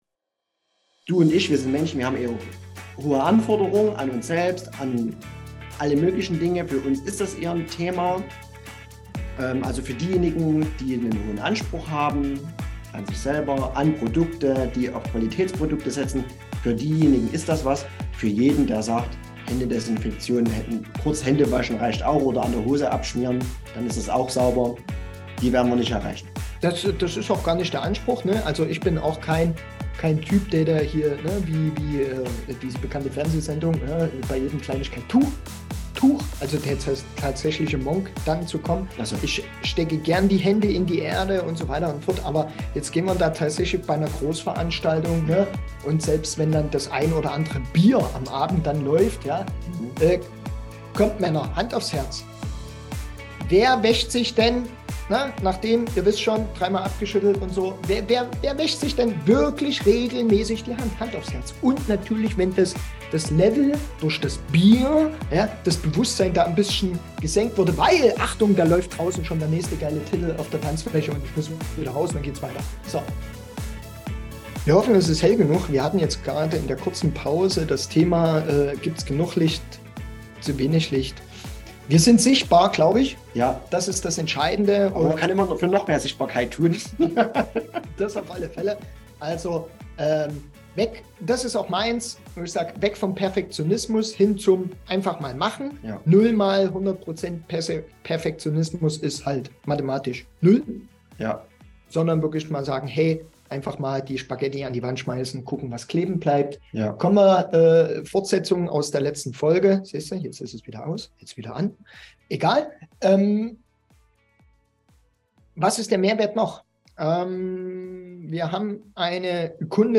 In dieser dritten Unterhaltung